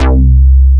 HOUSBAS2.wav